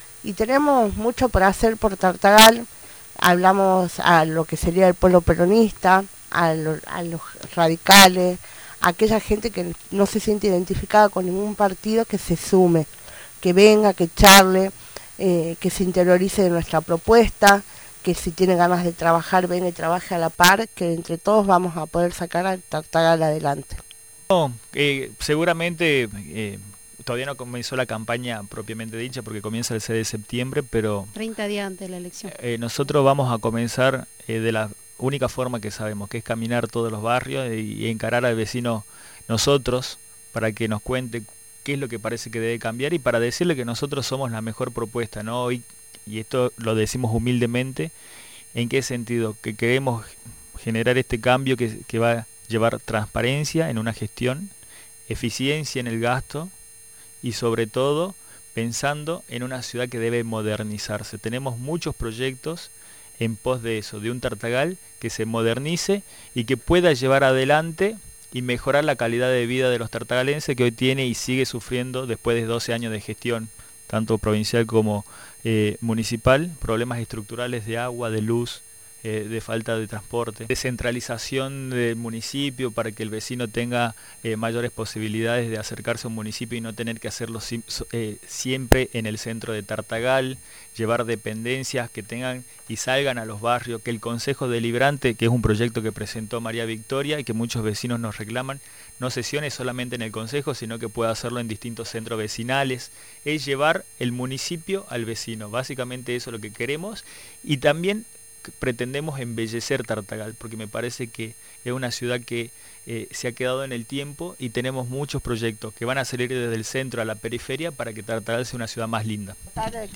Los concejales de Tartagal, Santiago Vargas y Victoria Bonillo, en su visita a los estudios de Radio Nacional, manifestaron por qué desean renovar su banca en las elecciones provinciales PASO. Los ediles señalaron la necesidad de cumplir con sus funciones y acercar las dependencias municipales a los barrios.